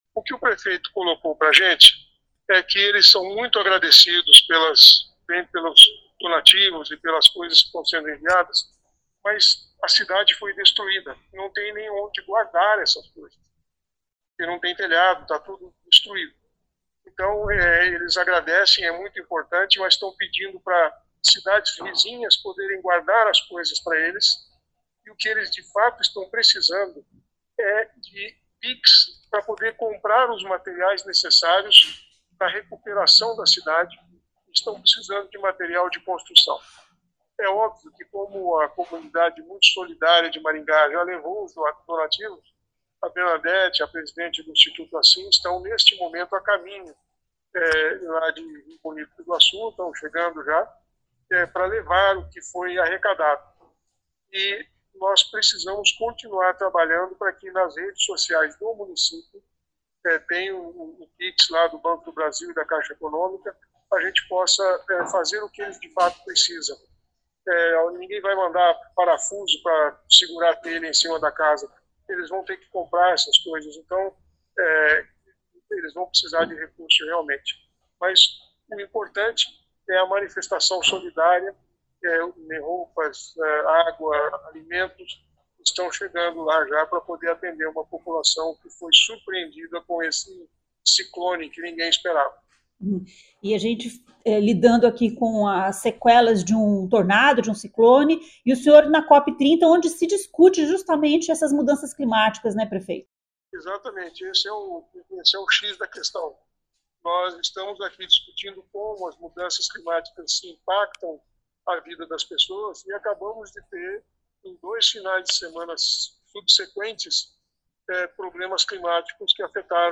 O prefeito Silvio Barros, que está na COP 30, conversou com o prefeito de Rio Bonito para entender o que a cidade mais precisa neste momento.